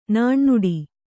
♪ nāṇnuḍi